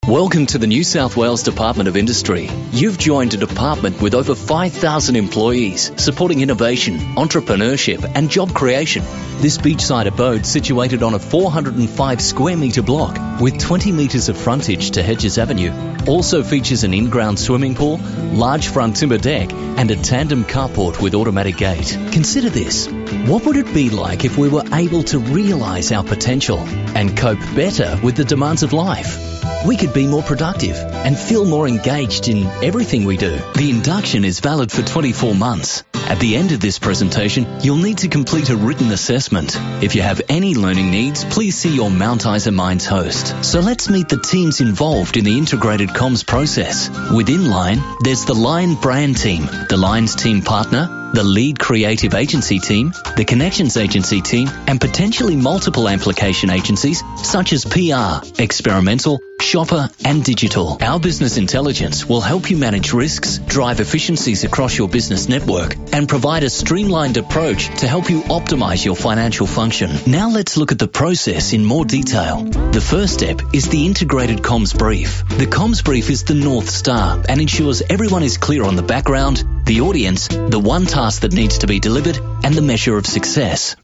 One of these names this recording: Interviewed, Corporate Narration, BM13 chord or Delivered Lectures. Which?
Corporate Narration